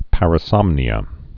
(părə-sŏmnē-ə)